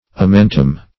amentum - definition of amentum - synonyms, pronunciation, spelling from Free Dictionary Search Result for " amentum" : The Collaborative International Dictionary of English v.0.48: Amentum \A*men"tum\, n.; pl. Amenta .